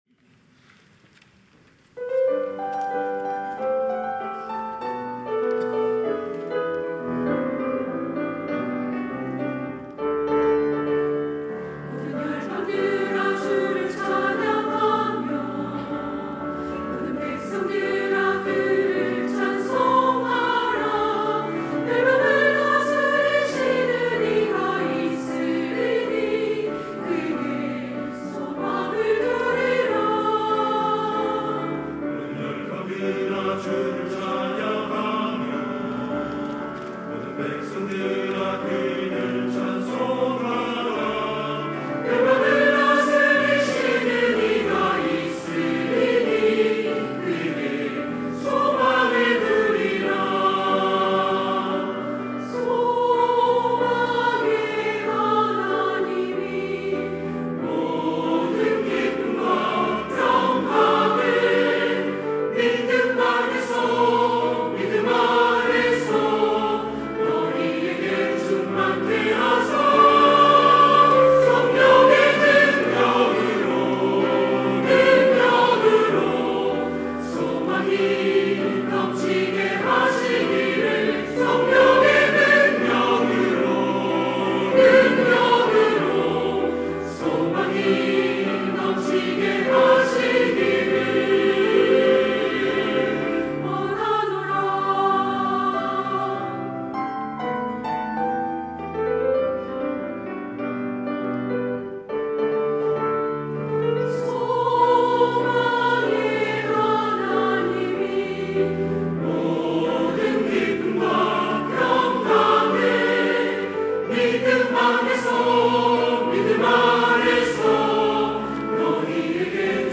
시온(주일1부) - 모든 열방들아
찬양대